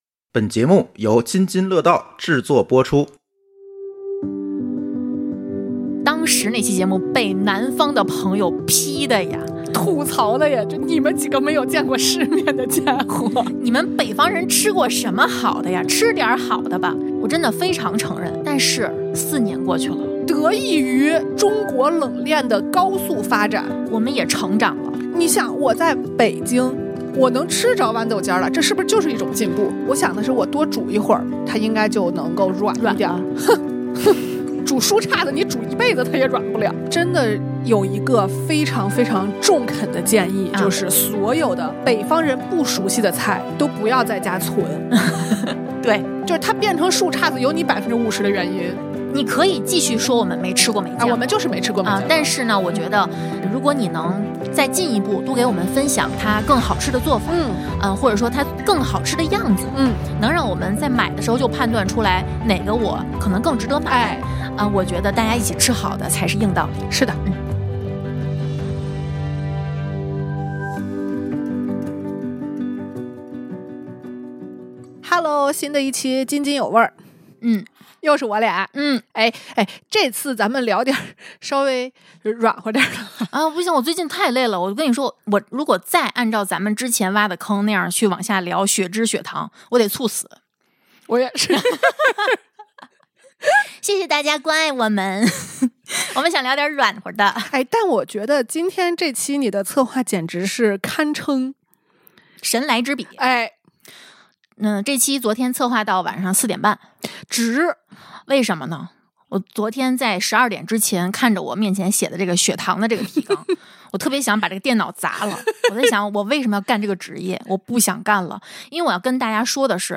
四年了，两个北方主播在吃春菜这件事上有什么长进吗？ | 津津有味/不叁不肆